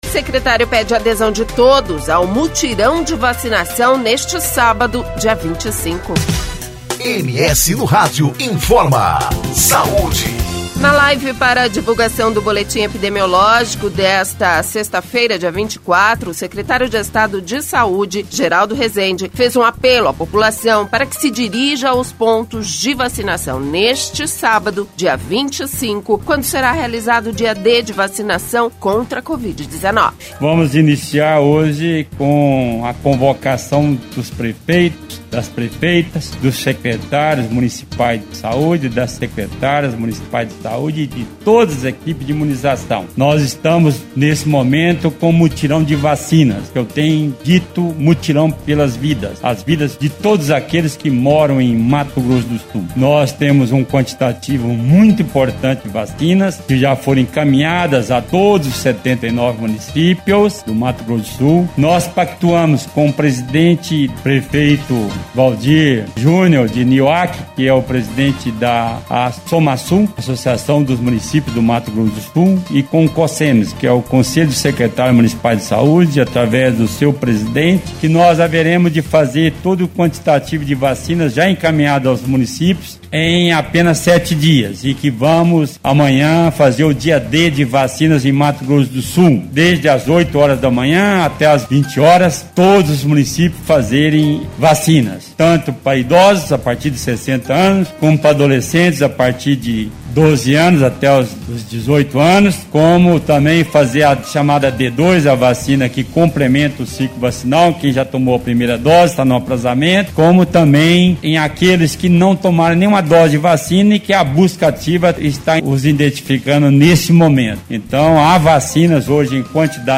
Na live para divulgação do boletim epidemiológico o Secretário de Estado de Saúde Geraldo Resende fez um apelo à população para que se dirija aos pontos de vacinação, neste sábado, dia 25, quando será realizado o dia D de vacinação contra a Covid-19.